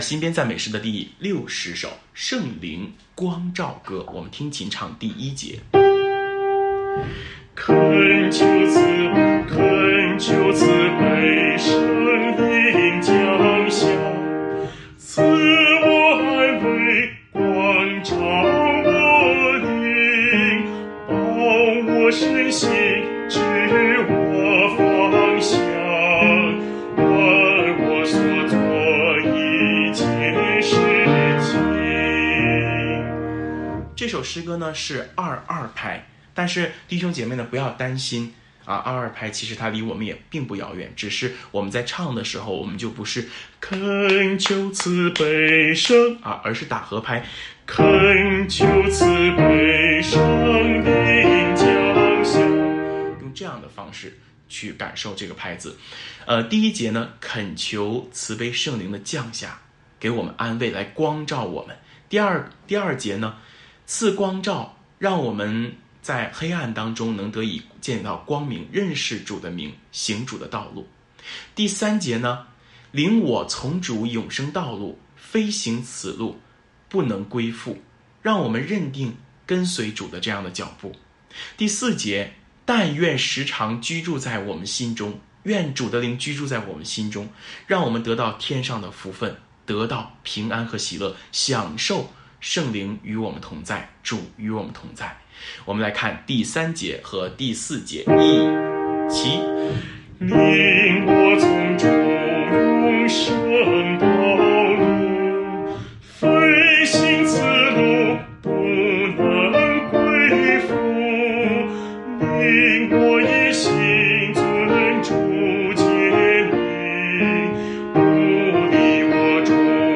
【颂唱练习】